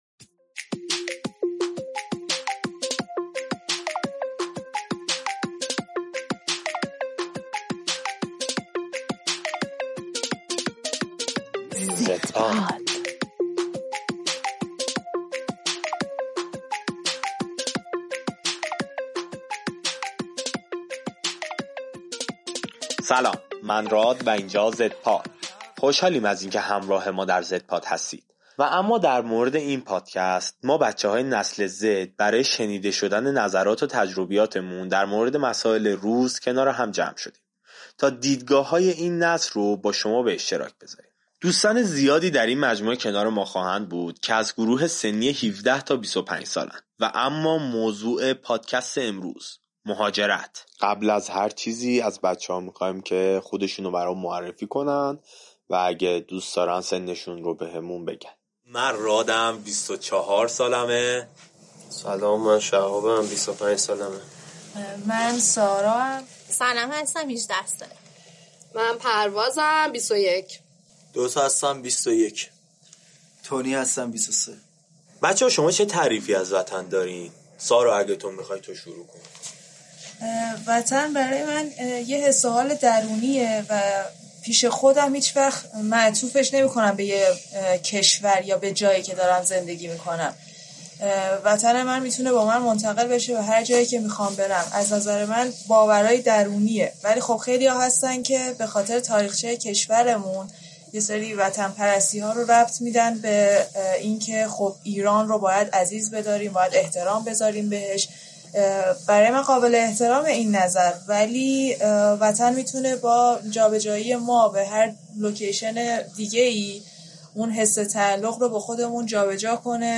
در اولین قسمت پادکست «زدپاد» گروهی از جوانان نسل زد، درباره این موضوع گفتگو می‌کنند.